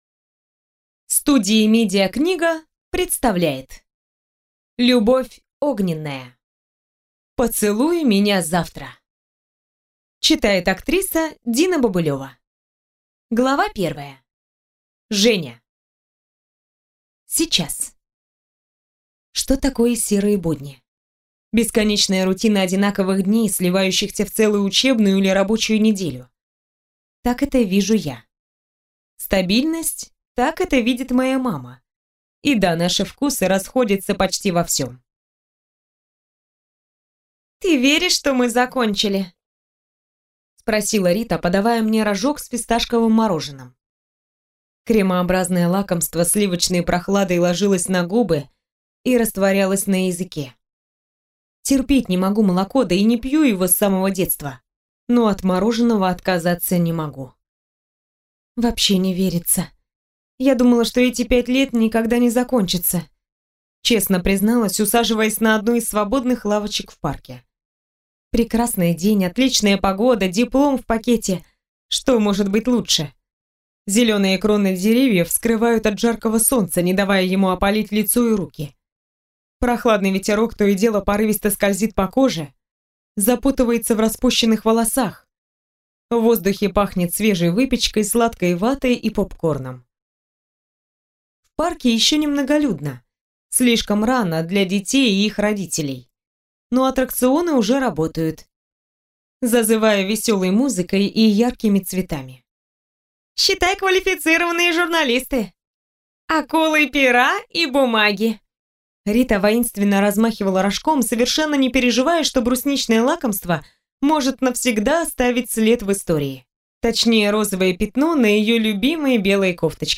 Аудиокнига Поцелуй меня завтра | Библиотека аудиокниг